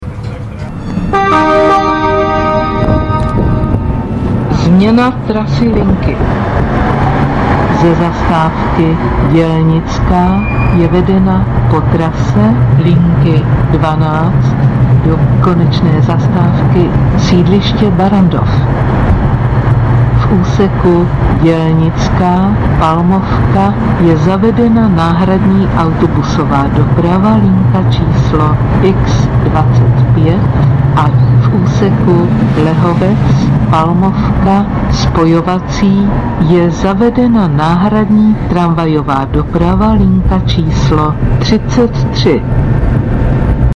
- Hlášení o výluce v tramvajích si